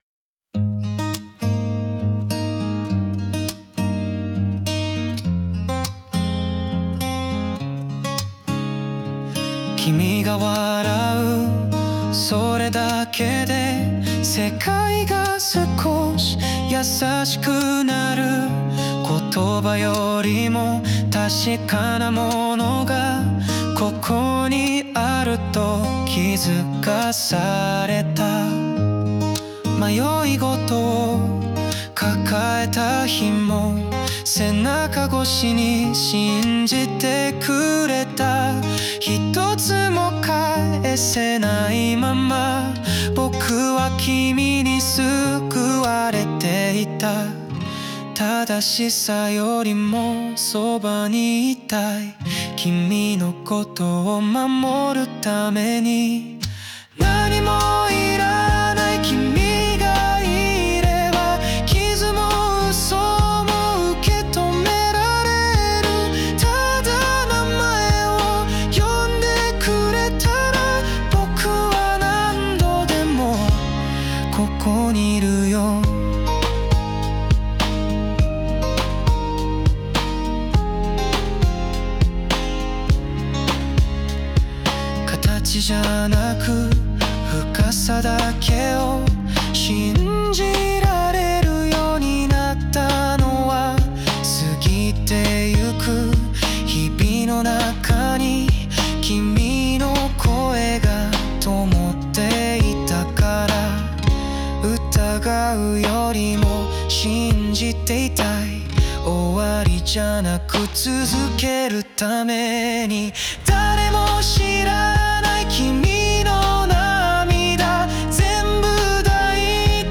邦楽男性ボーカル著作権フリーBGM ボーカル
著作権フリーオリジナルBGMです。
男性ボーカル（邦楽・日本語）曲です。
曲調もやわらかく、まっすぐな愛を歌うラブソングです。